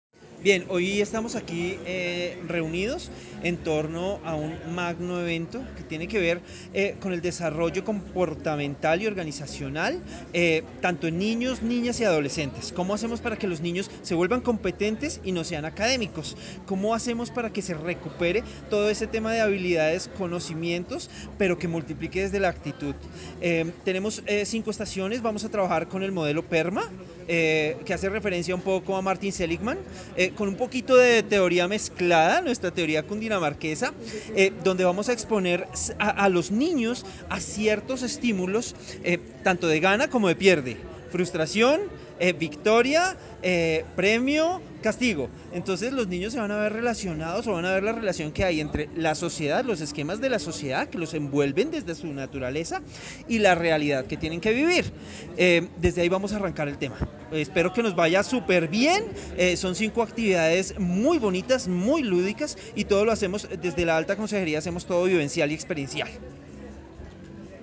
Entrevista Alto Consejero para la Felicidad y el Bienestar de Cundinamarca Manuel Darío Carvajal Trillos
Entrevista+alto+Consejero.mp3